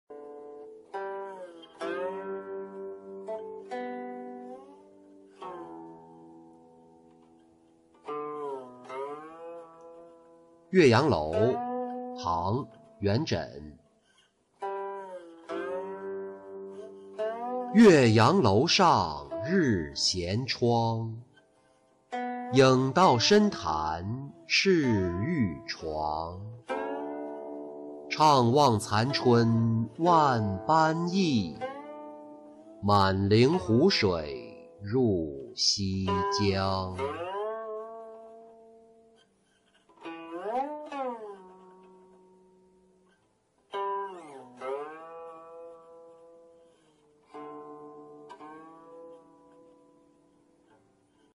岳阳楼-音频朗读